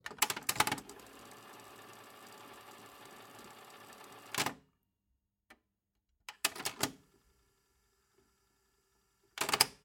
Звуки аудиокассеты
Окунитесь в атмосферу прошлого с подборкой звуков аудиокассет: характерное шипение пленки, щелчки перемотки, фоновый шум магнитофона.
Шуршание перемотки кассеты в аудиомагнитофоне